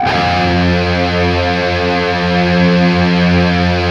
LEAD F 1 LP.wav